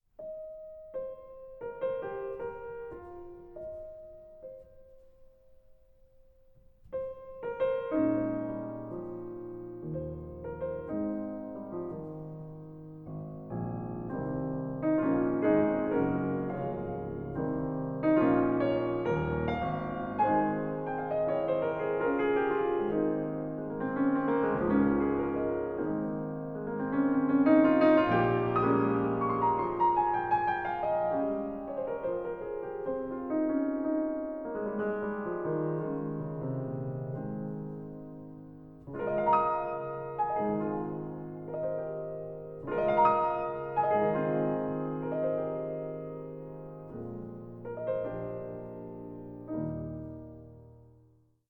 Multi-channel / Stereo
piano